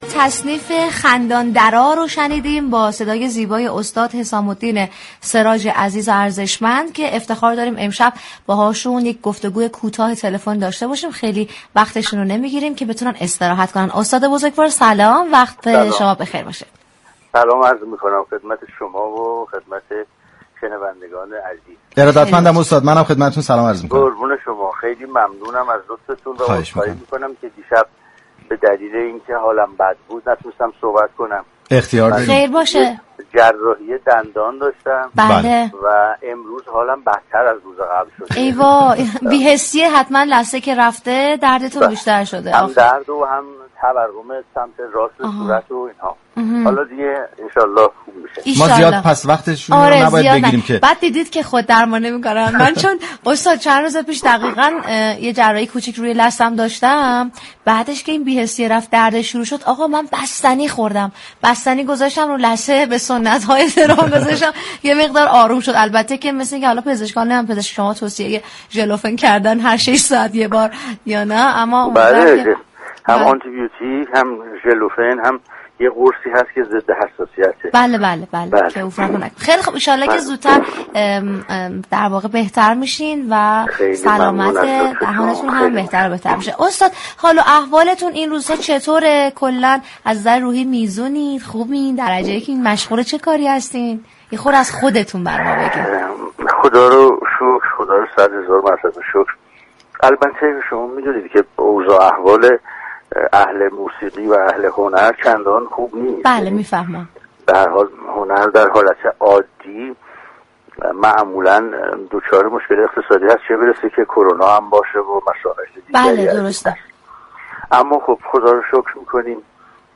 به گزارش روابط عمومی رادیو صبا ، "مثبت صباهنگ " جنگ عصرگاهی بر پایه موسیقی و گفتگوی صمیمی در رادیو صبا است، كه با محوریت پخش ترانه وموسیقی های شاد راهی آنتن صبا می شود .
این برنامه روز جمعه 5 میزبان تلفنی حسام الدین سراج خواننده موسیقی های سنتی ایرانی و نوازندهٔ خوب كشورمان شد .